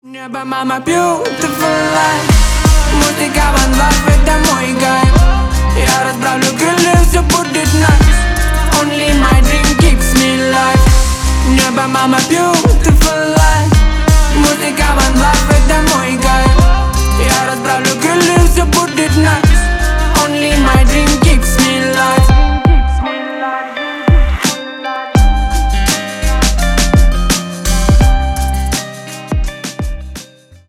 Рэп и Хип Хоп
спокойные